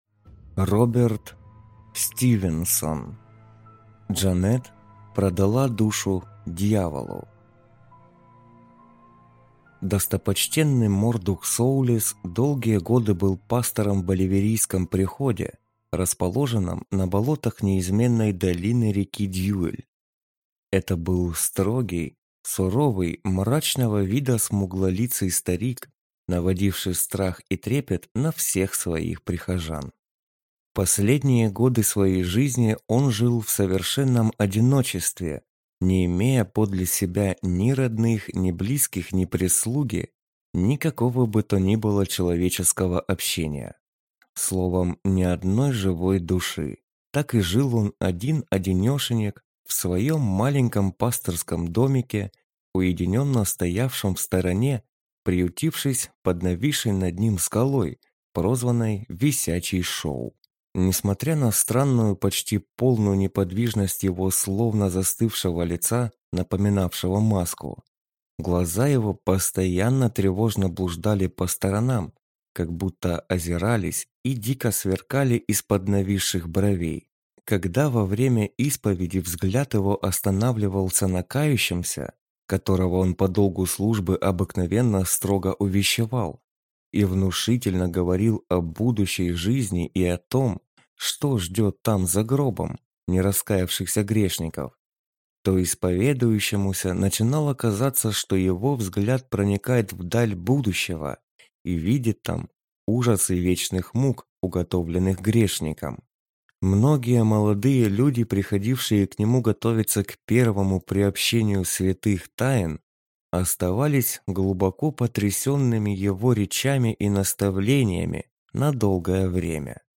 Аудиокнига Джанет продала душу дьяволу | Библиотека аудиокниг